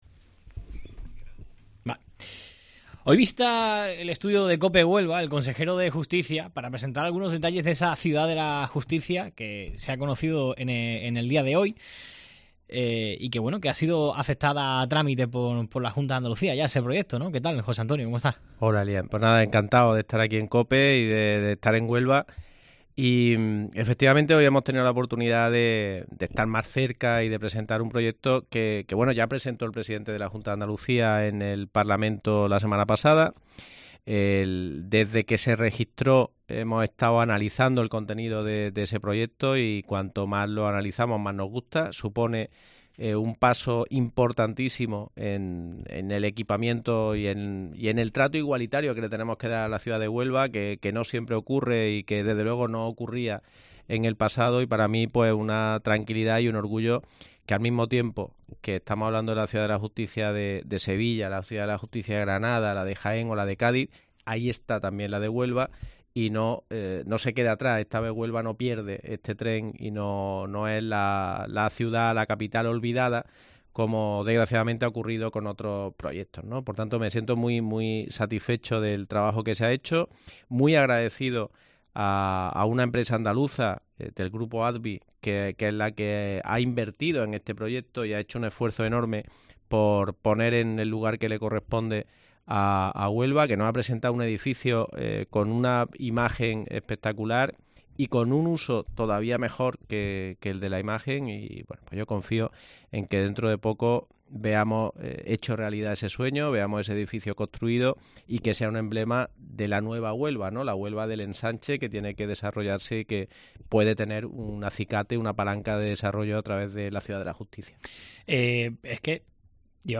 Entrevista completa a José Antonio Nieto, consejero de Justicia de la Junta de Andalucía